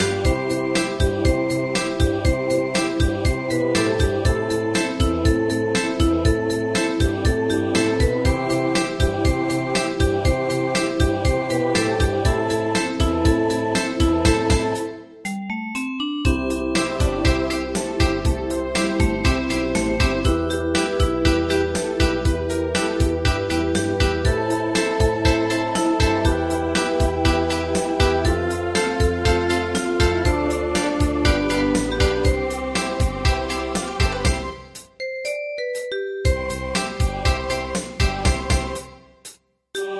大正琴の「楽譜、練習用の音」データのセットをダウンロードで『すぐに』お届け！
カテゴリー: アンサンブル（合奏） .
映画音楽・軽音楽